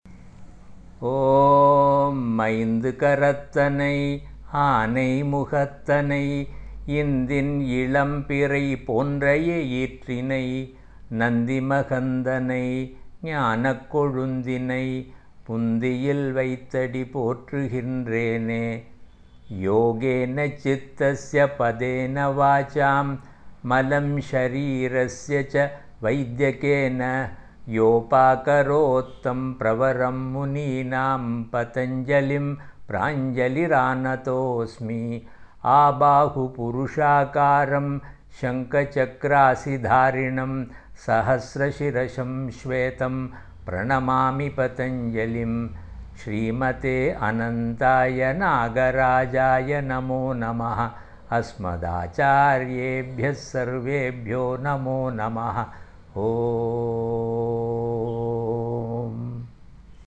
Ganeshaandyogaprayer.mp3